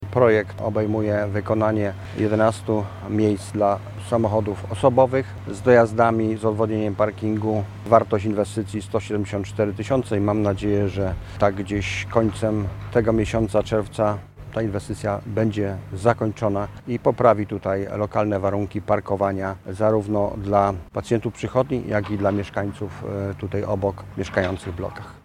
To się już wkrótce zmieni zapewnia Stanisław Lonczak, Starosta Mielecki.